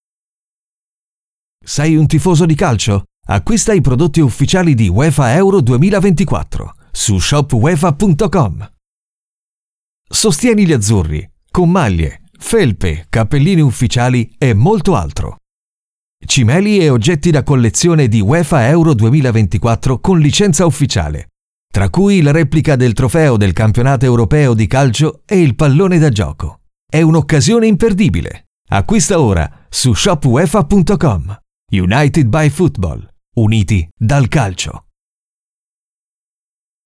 Natural, Versátil, Seguro, Maduro, Suave